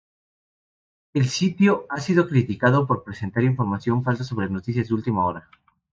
Pronounced as (IPA) /pɾesenˈtaɾ/